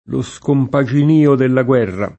scompaginio